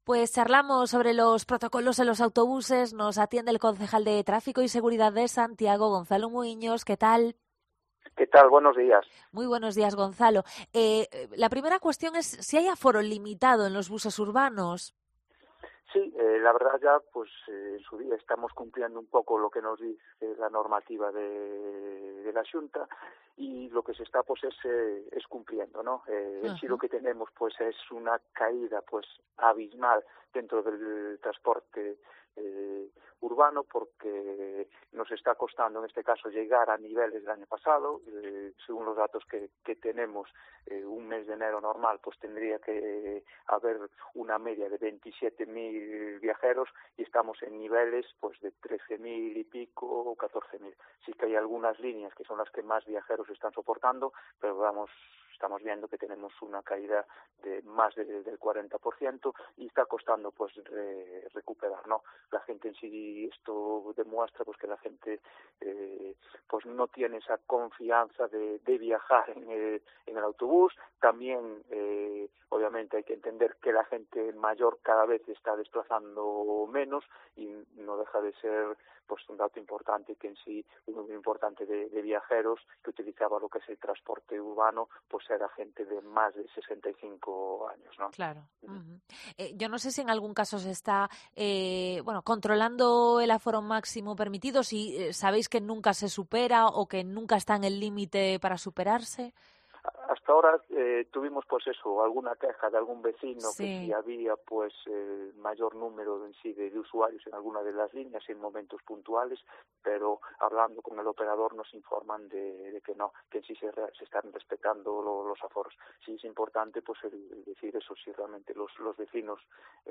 El concejal Gonzalo Muíños habla en COPE sobre los protocolos anticovid en los buses